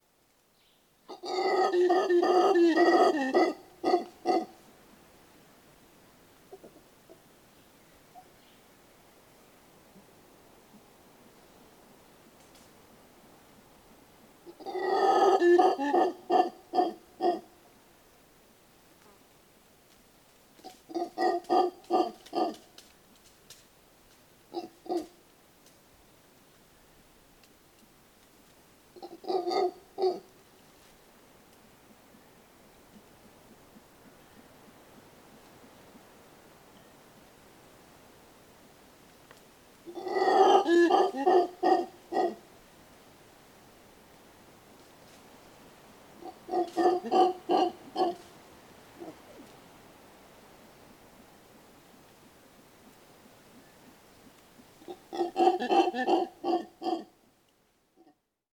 На этой странице собраны разнообразные звуки ревунов — от громких рыков до отдаленных эхо в джунглях.
Голос обезьяны-ревуна